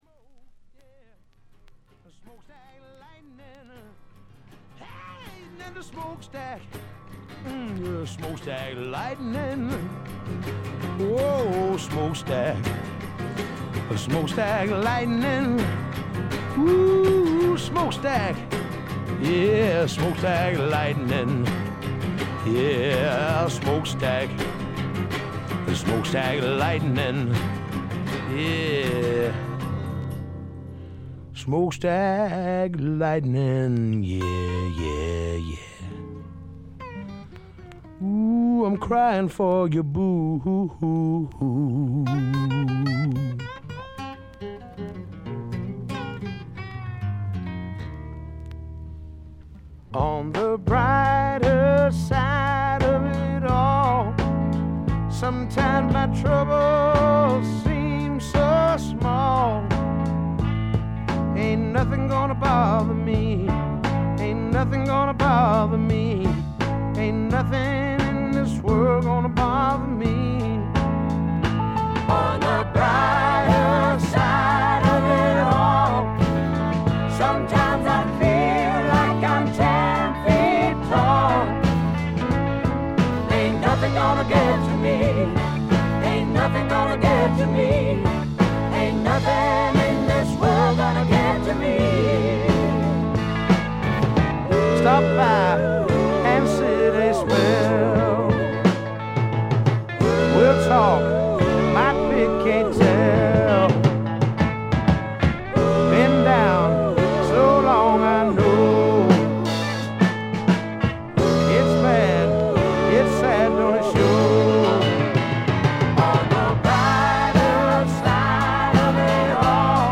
スワンプ基本盤。
試聴曲は現品からの取り込み音源です。